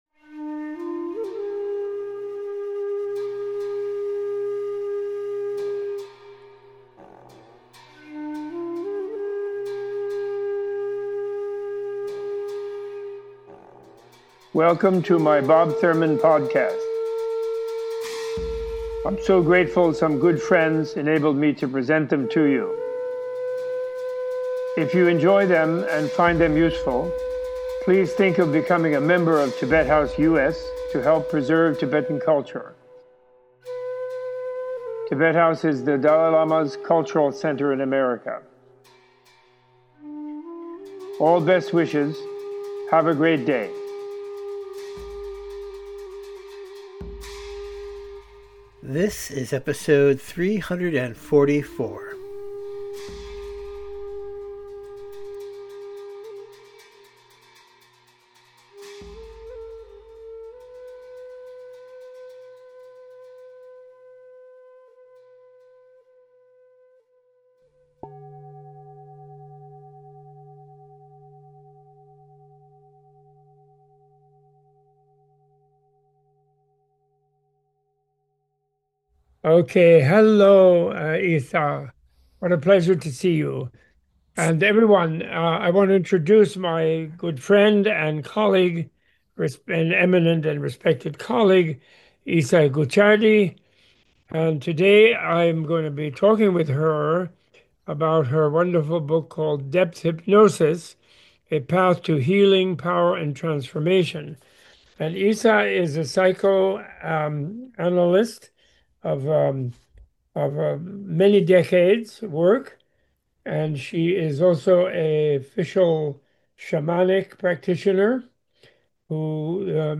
A Tibet House US Menla Conversation